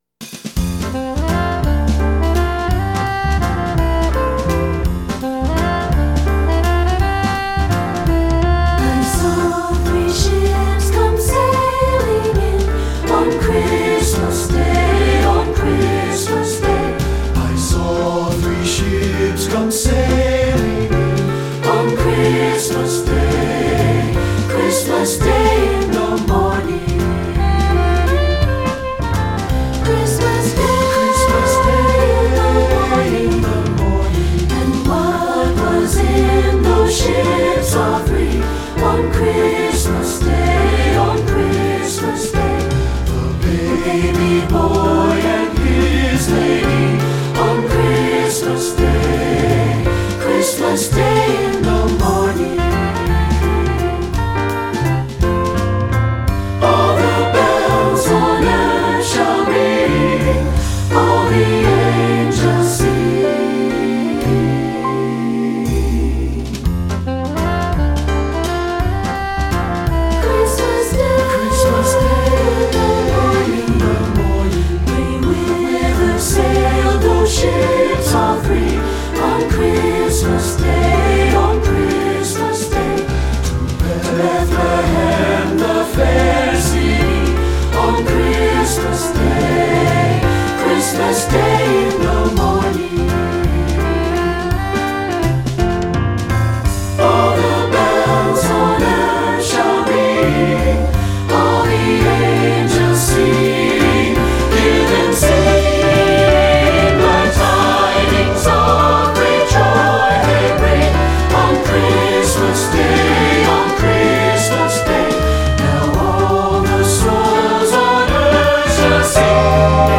Composer: English Carol
Voicing: SATB and Piano